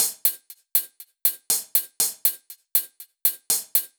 Index of /musicradar/french-house-chillout-samples/120bpm/Beats
FHC_BeatB_120-03_Hats.wav